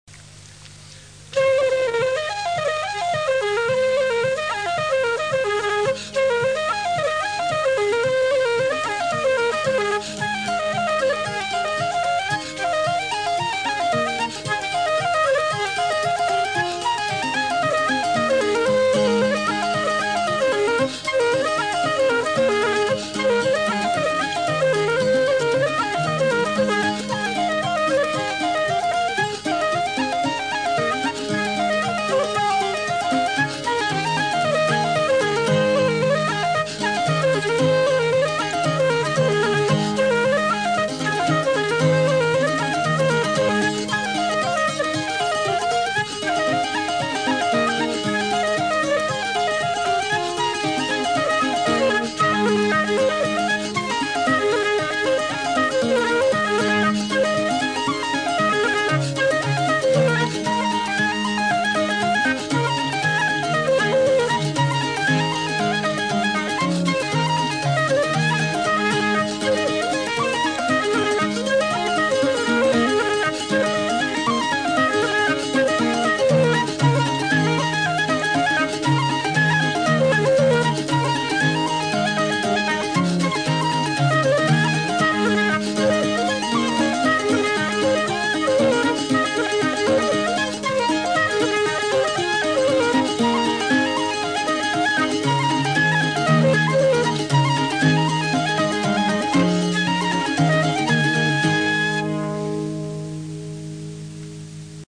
This is a mysterious flute tape which was passed around on IRTRAD-L a while ago.
flute-cittern.mp3